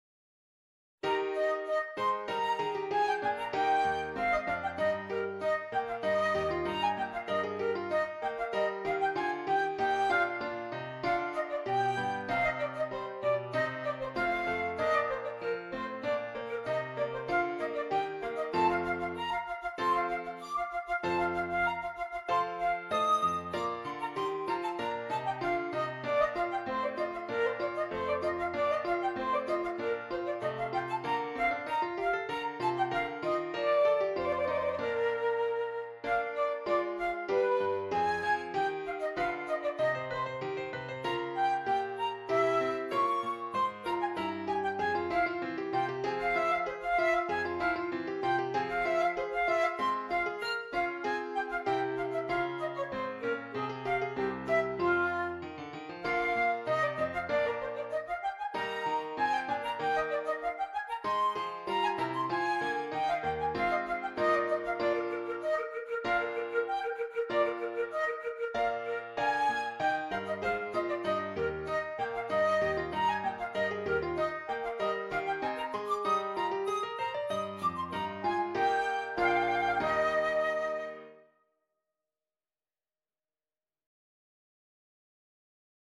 Flute and Keyboard
This short tuneful piece would work well on any concert.